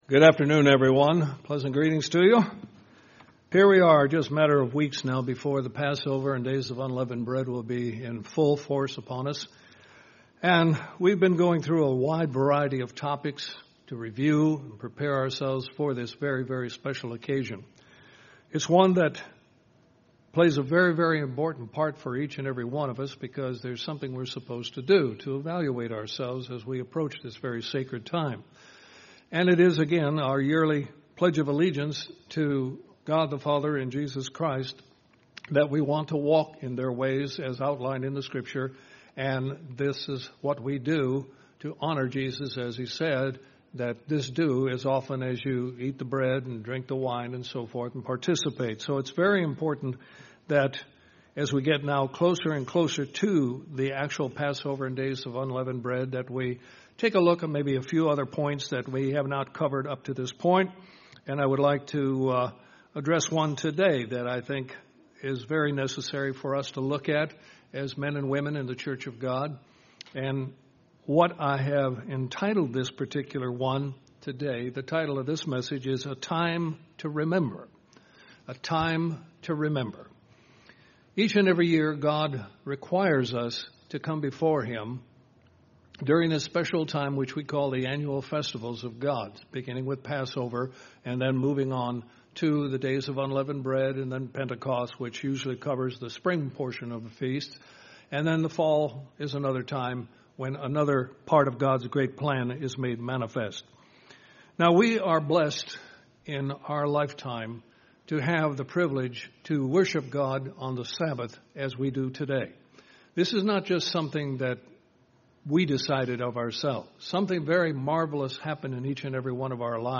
This sermon focuses on preparation for Passover.